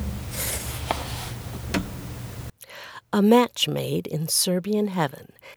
It sounds like you tripped over the hassock on the way to your desk.